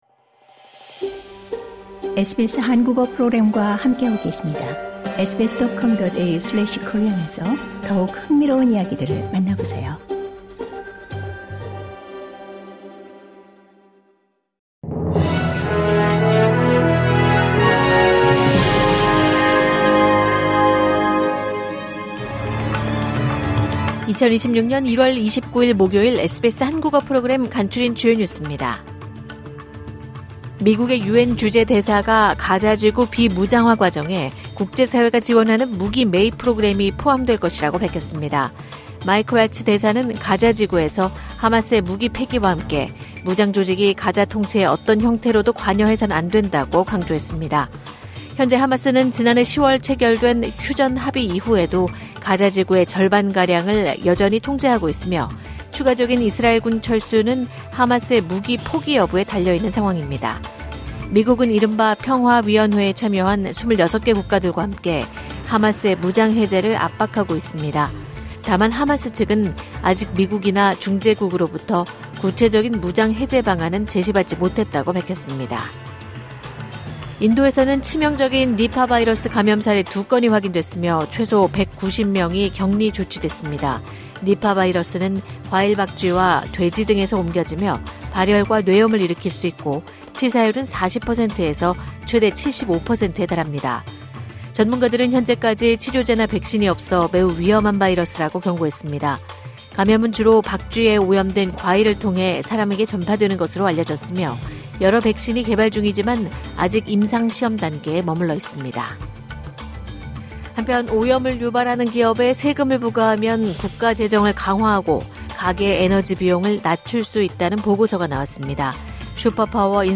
국립공원 재발화 경고 지속 이 시각 간추린 주요 뉴스 LISTEN TO 호주 뉴스 3분 브리핑: 2026년 1월 29일 목요일 SBS Korean 03:33 Korean 미국의 유엔 주재 대사가 가자지구 비무장화 과정에 국제사회가 지원하는 무기 매입 프로그램이 포함될 것이라고 밝혔습니다.